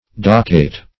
docquet - definition of docquet - synonyms, pronunciation, spelling from Free Dictionary Search Result for " docquet" : The Collaborative International Dictionary of English v.0.48: docquet \doc"quet\, n. & v. See Docket .